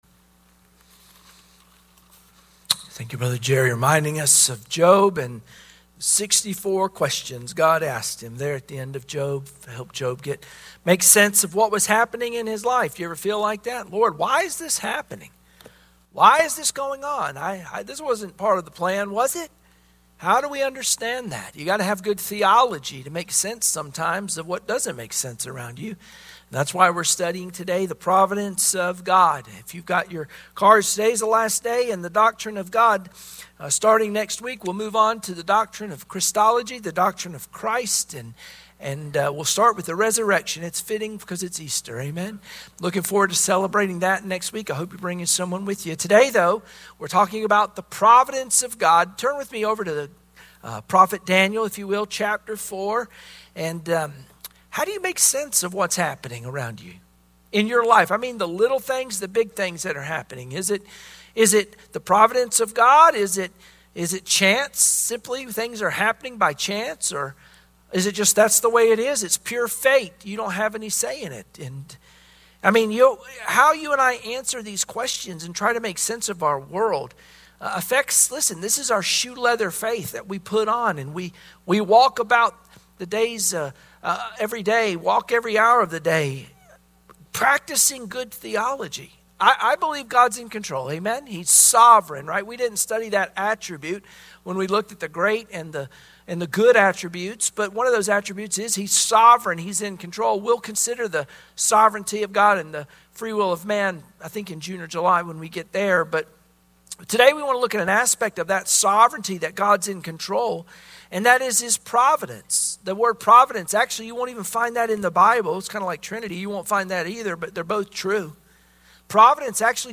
Sunday Morning Worship Passage: Daniel 4 Service Type: Sunday Morning Worship Share this